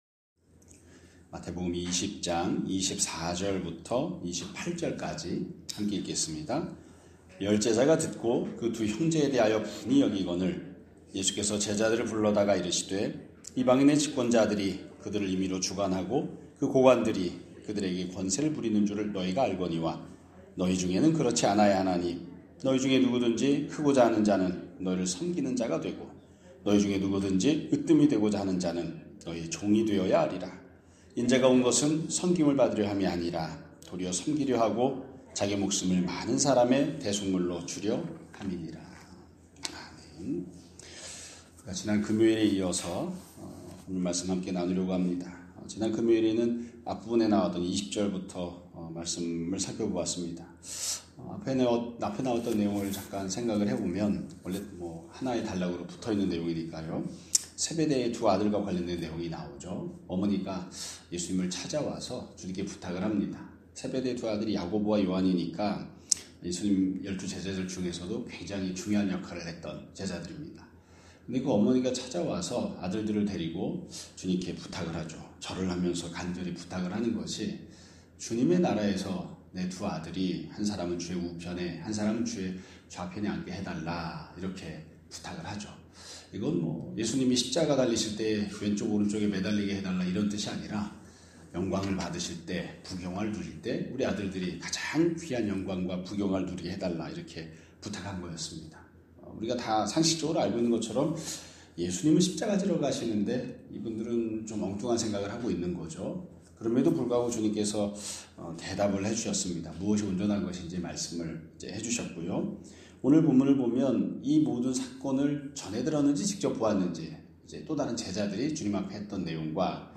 2026년 1월 19일 (월요일) <아침예배> 설교입니다.